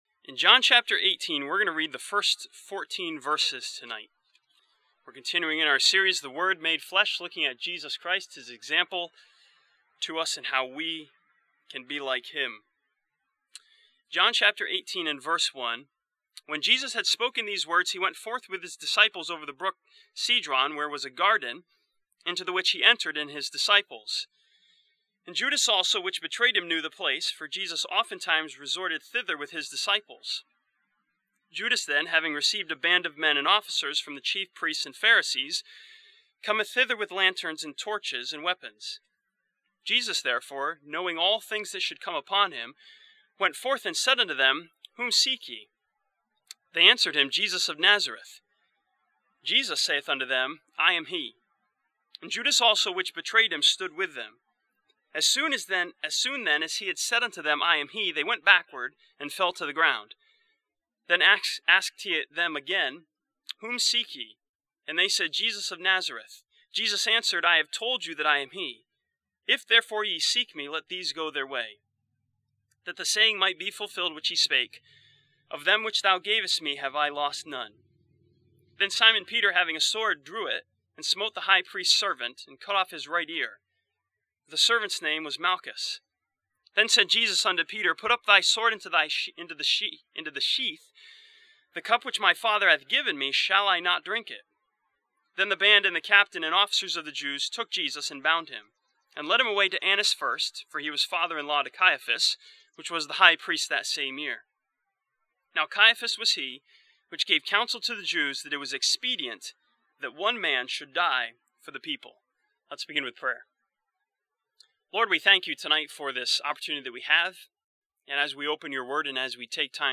This sermon from John chapter 18 studies the example of Jesus Christ as He gave up to the will and way of His heavenly Father.